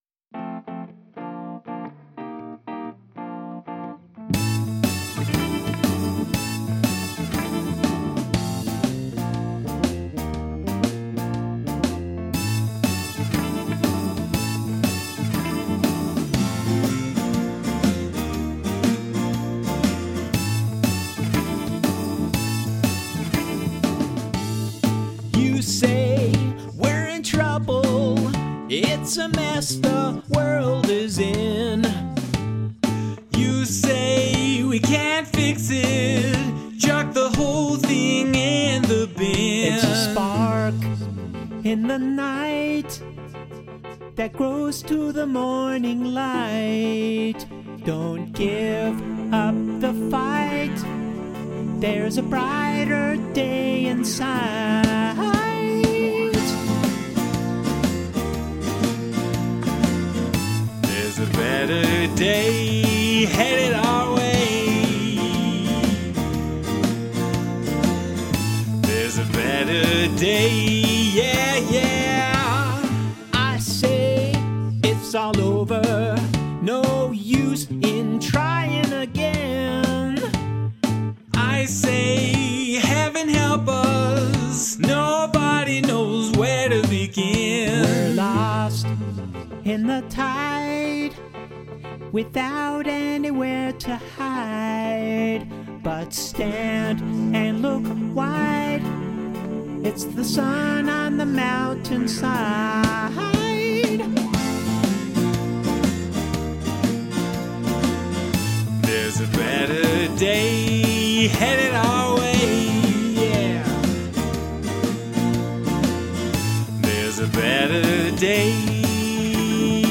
vocals, organ, electric piano, horns, drums, piano, bass
vocals, guitars, bass, tambourine
The bass sound took a lot of sculpting.
I like your voices and how they blend.